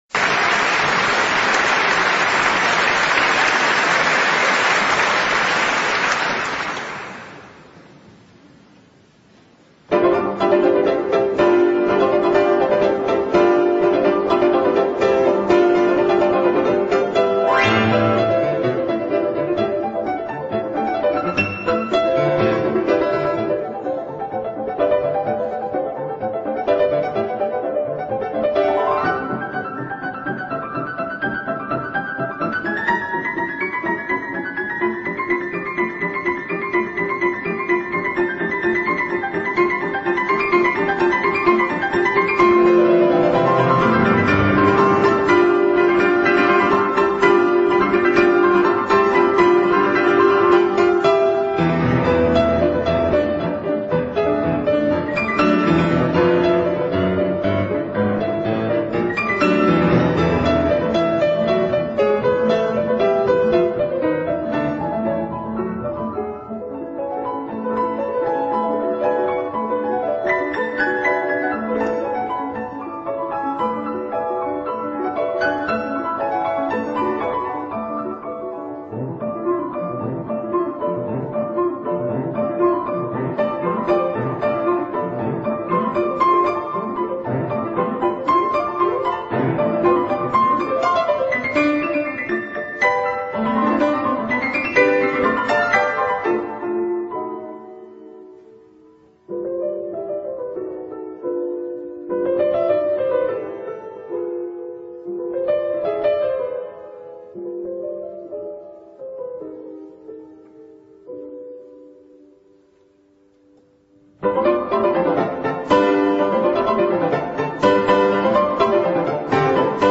现场